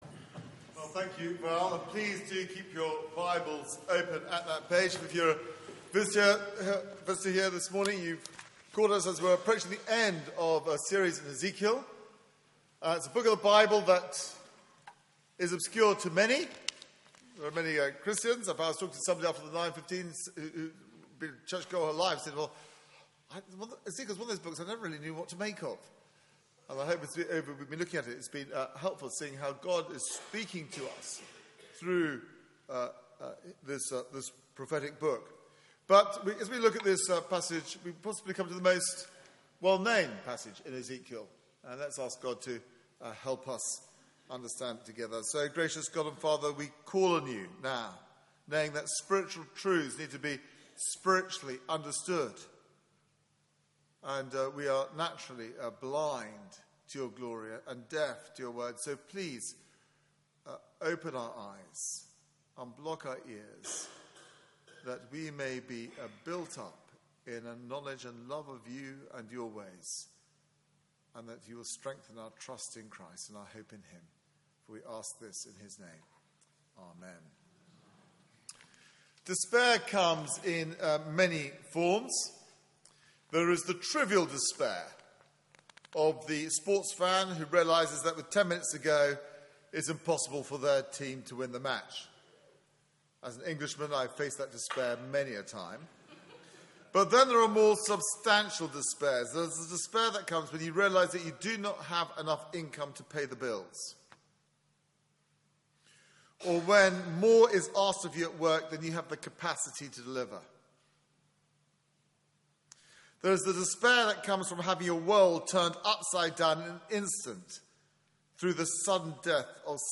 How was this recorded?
Media for 9:15am Service on Sun 21st Feb 2016